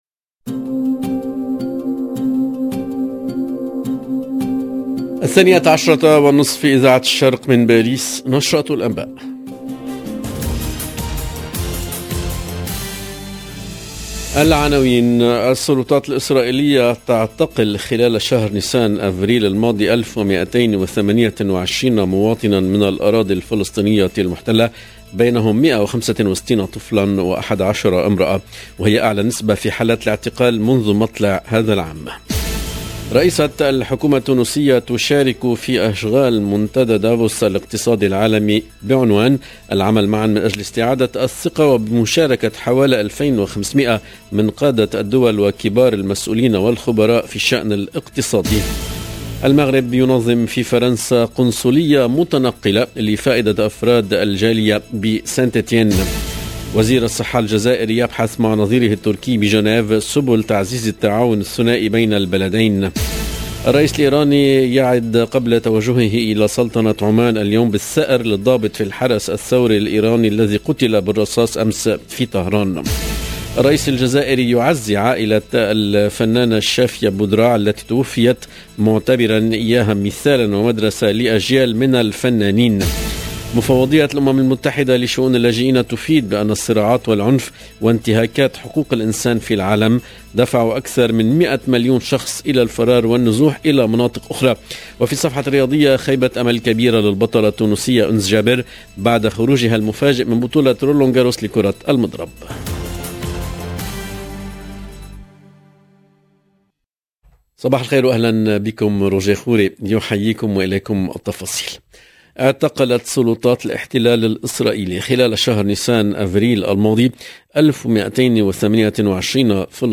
LE JOURNAL DE LA MI-JOURNEE EN LANGUE ARABE DU 23/05/22